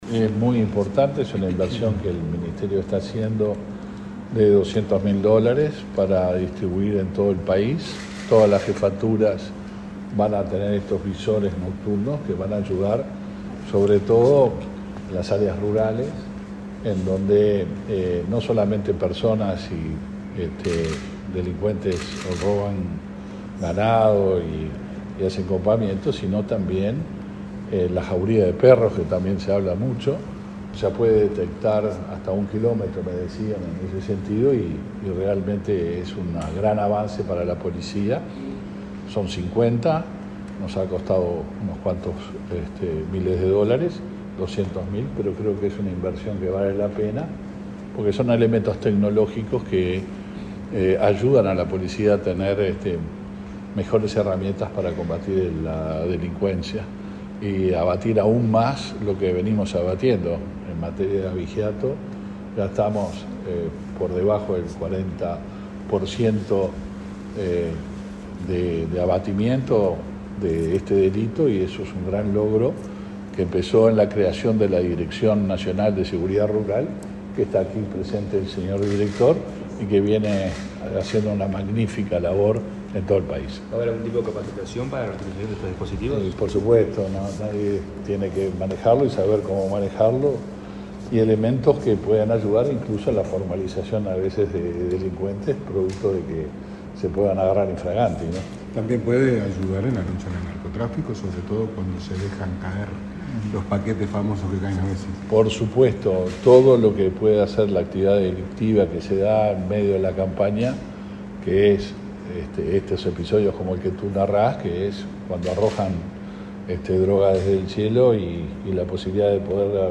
Declaraciones del ministro del Interior, Luis Alberto Heber
Luego dialogó con la prensa.